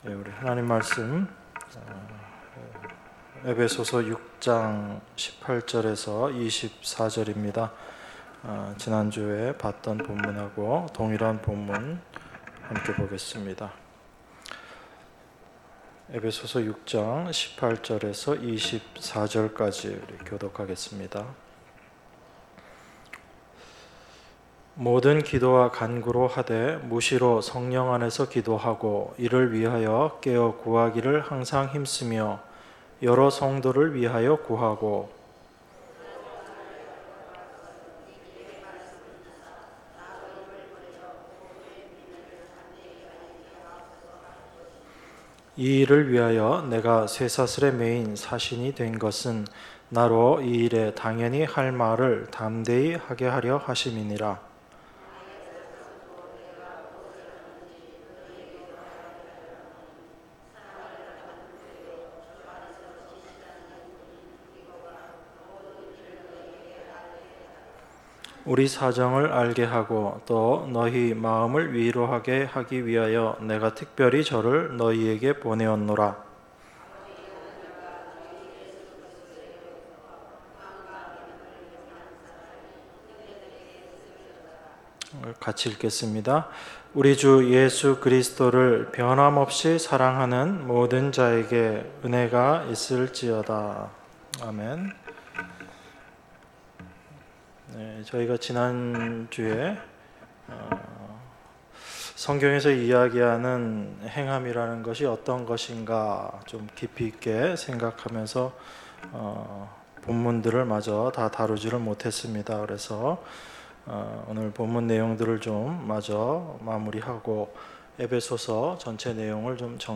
주일예배 - 에베소서 6장 18절~24절 주일2부